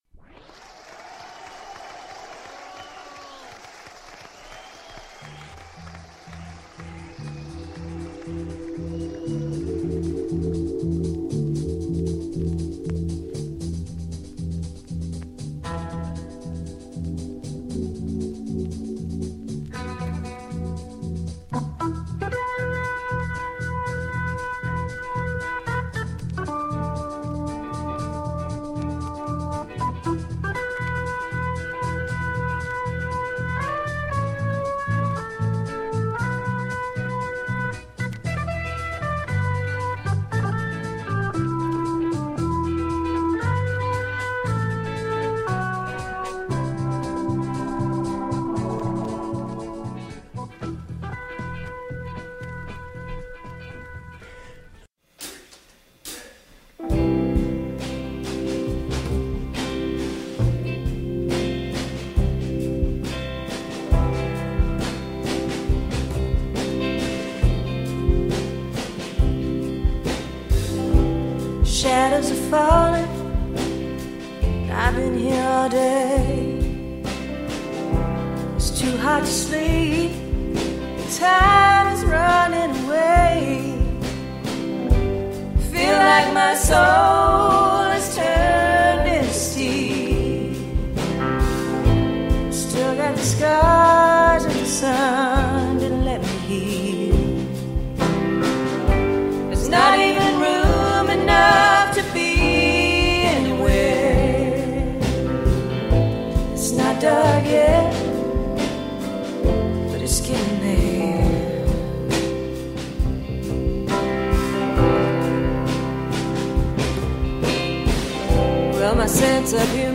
3pm Informal interviews with artists, writers and othe...